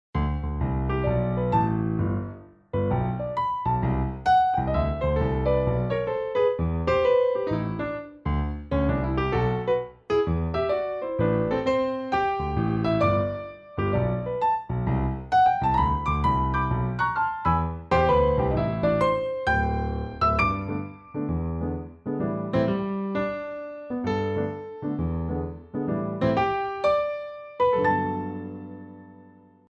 фортепиано , приятные
джаз